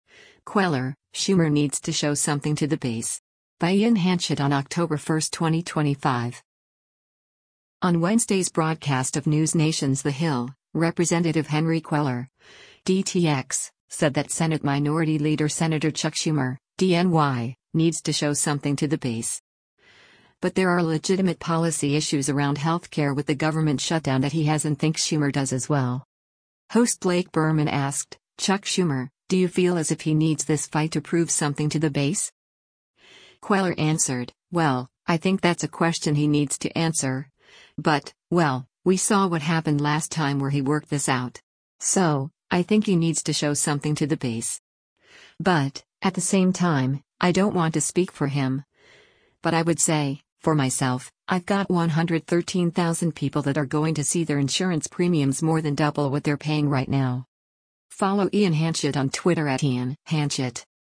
On Wednesday’s broadcast of NewsNation’s “The Hill,” Rep. Henry Cuellar (D-TX) said that Senate Minority Leader Sen. Chuck Schumer (D-NY) “needs to show something to the base.” But there are legitimate policy issues around health care with the government shutdown that he has and thinks Schumer does as well.